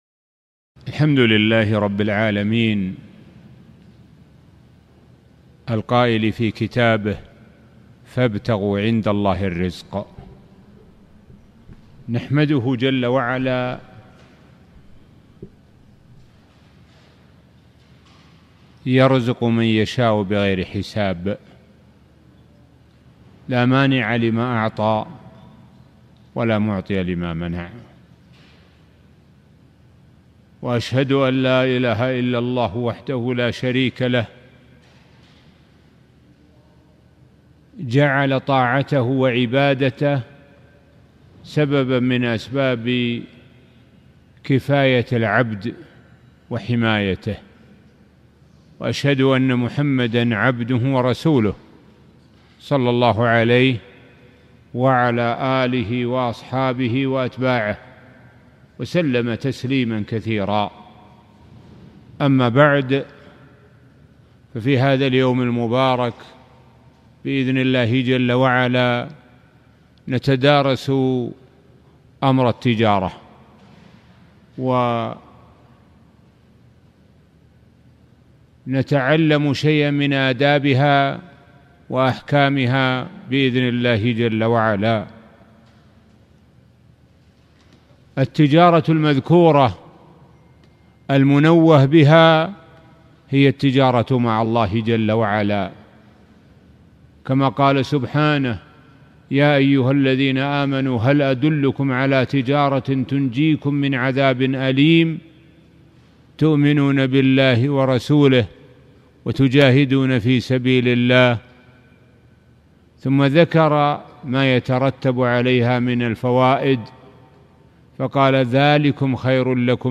محاضرة - أدب التجارة - دروس الكويت